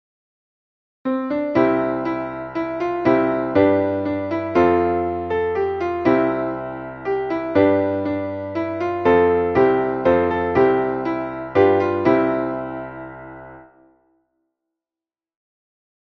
Traditionelles Winterlied